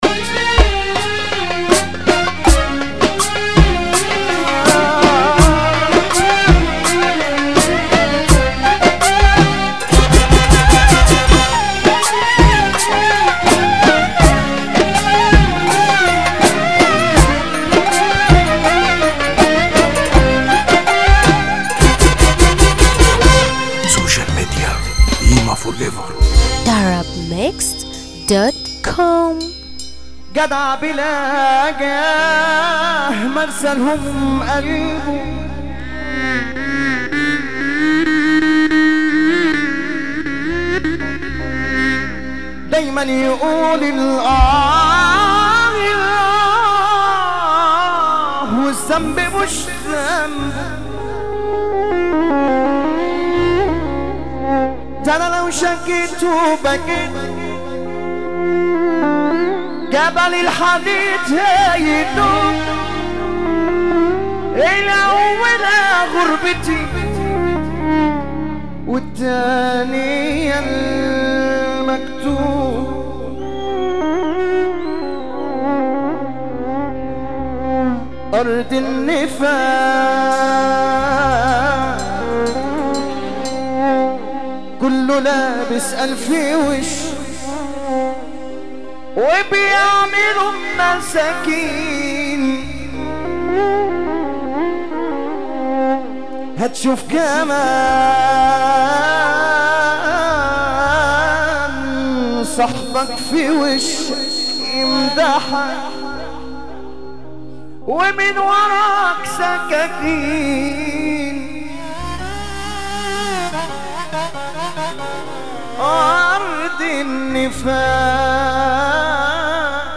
موال
حزينة موت